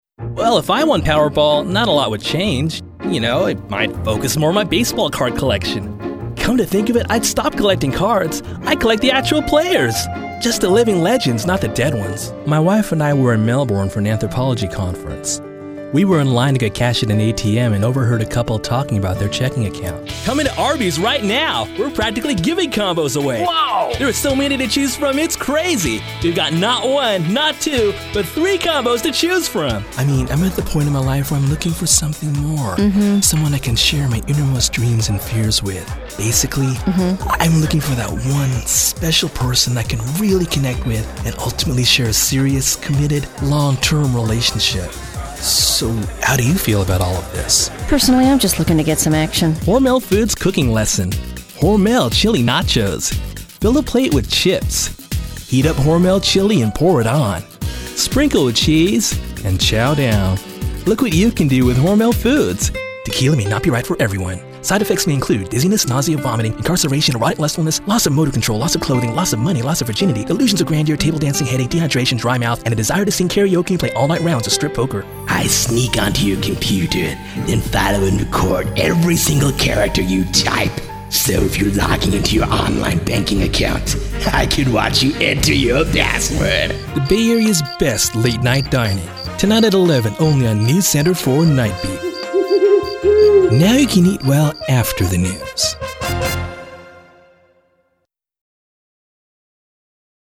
Young Male Talent: I do commercials and narration.
Sprechprobe: Werbung (Muttersprache):